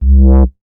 MoogSubVoc 003.WAV